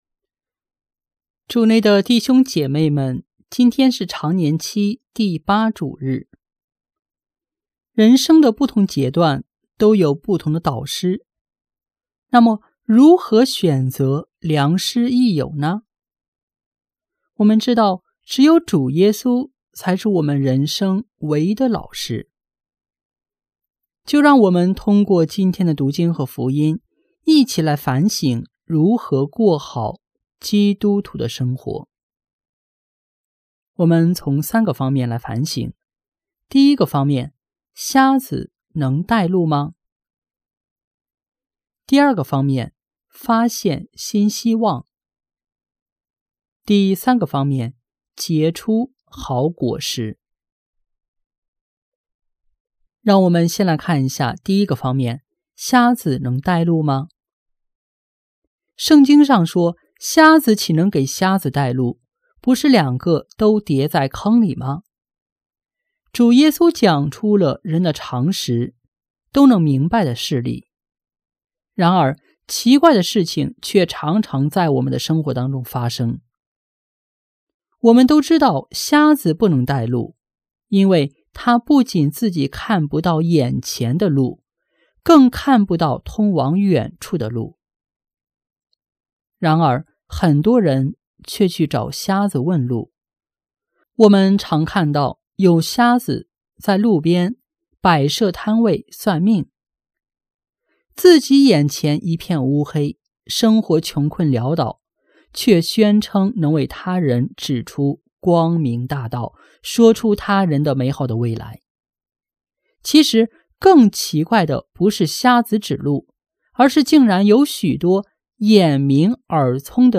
【主日证道】| 发现希望结善果（丙-常年期第8主日）